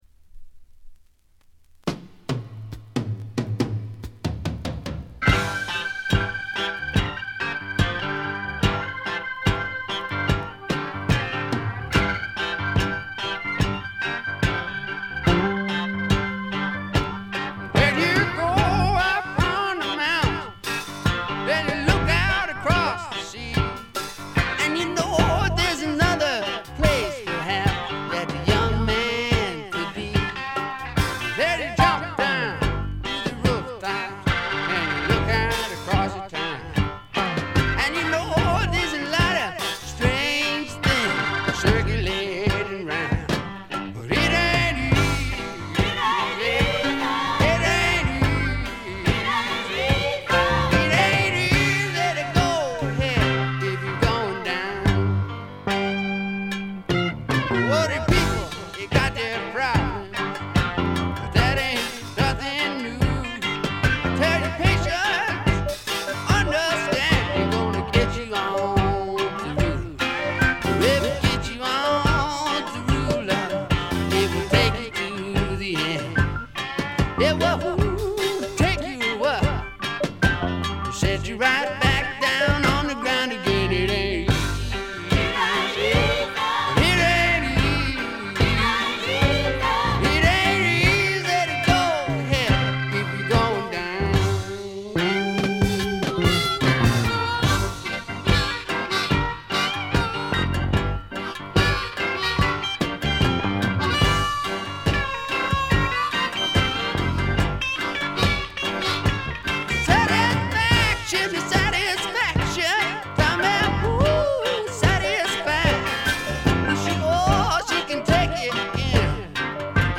ごくわずかなノイズ感のみ。
独特のしゃがれた渋いヴォーカルで、スワンプ本線からメローグルーヴ系までをこなします。
試聴曲は現品からの取り込み音源です。